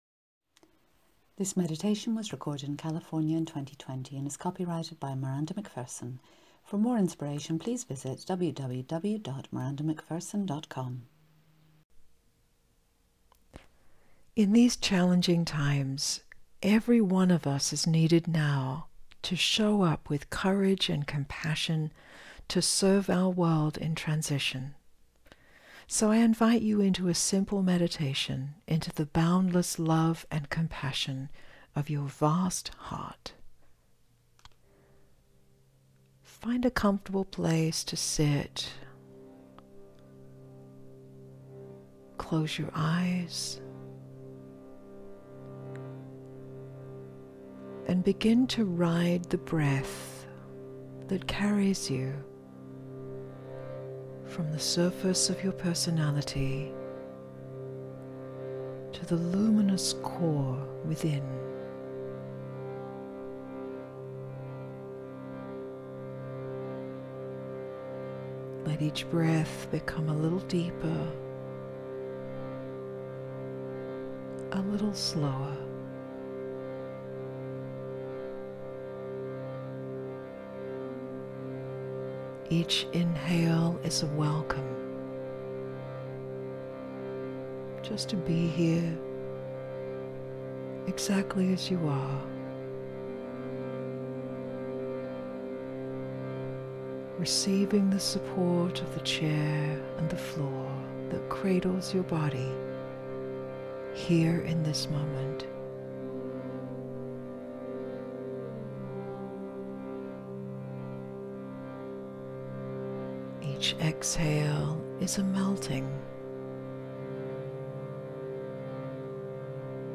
The Heart of Absolute Compassion Meditation